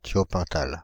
Ääntäminen
France (Île-de-France): IPA: /tjɔ.pɛ̃.tal/